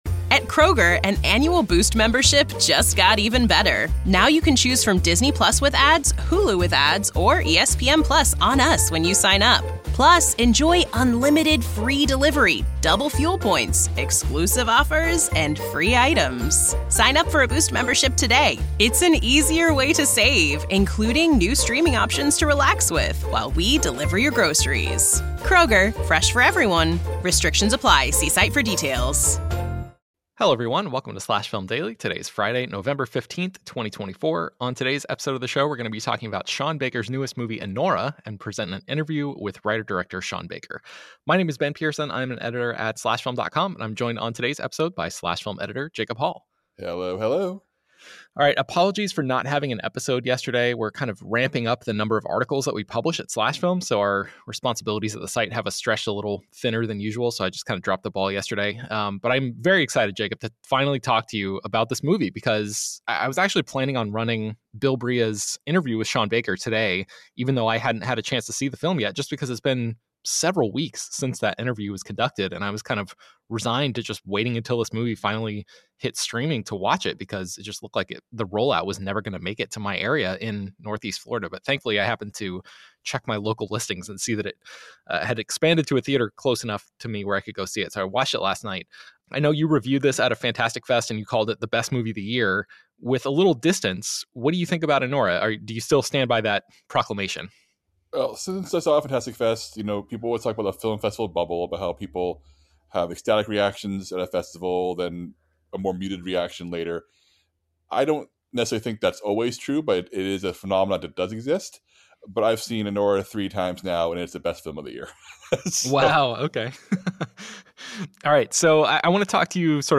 present an interview with writer/director Sean Baker